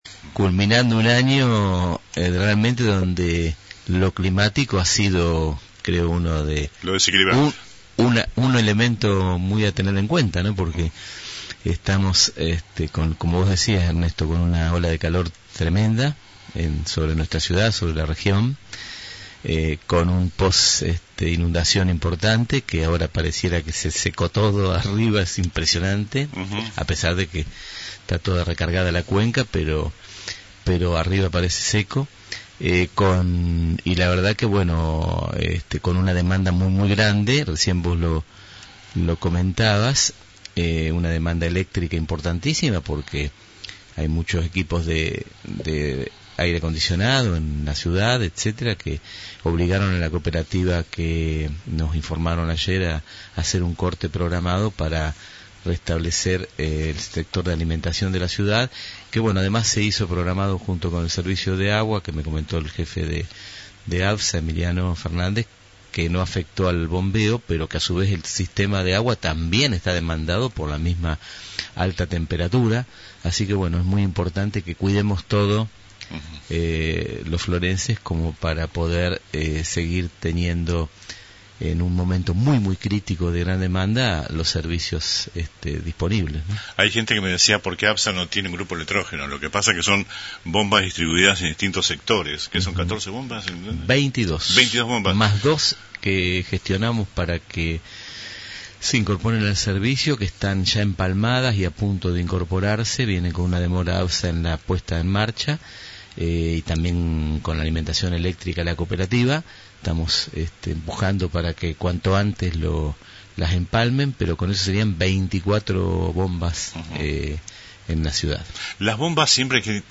En el último día del 2025, el intendente municipal visitó los estudios de la radio. En una entrevista mano a mano